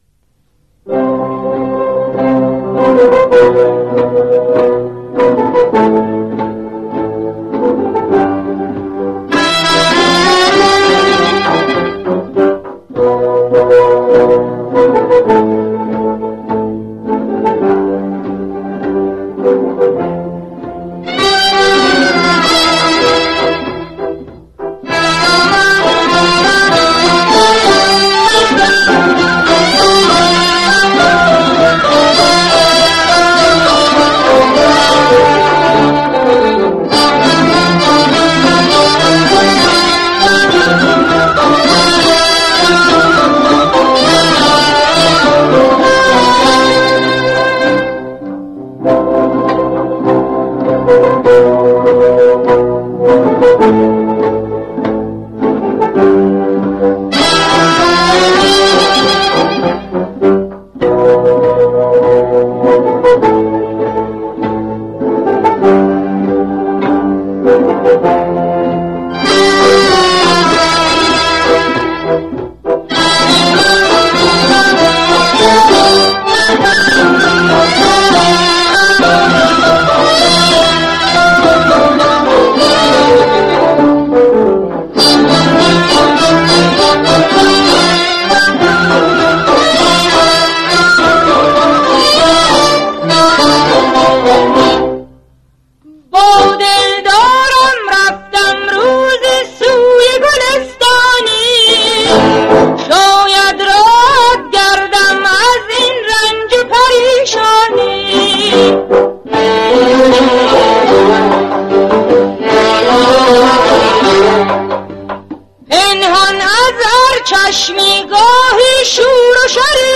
دستگاه: ماهور